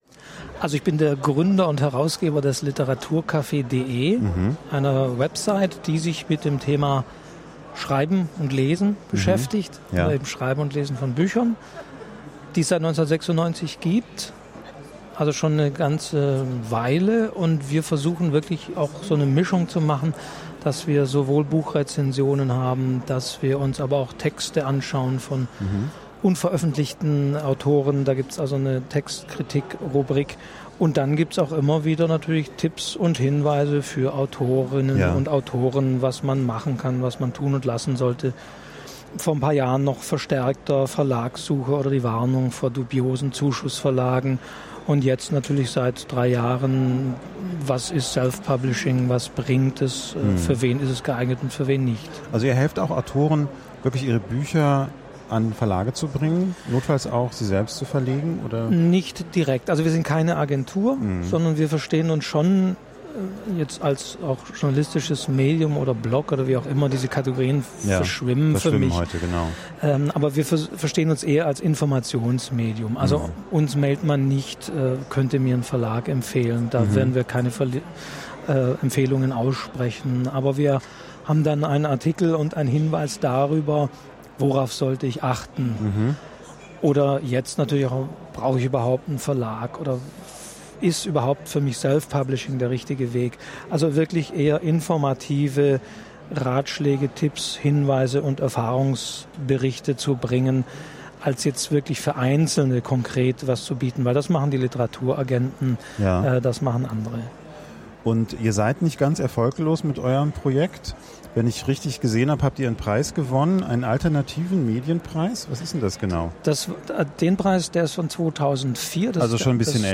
Die Buchmesse-Folge aus Leipzig.